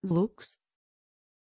Aussprache von Lux
pronunciation-lux-tr.mp3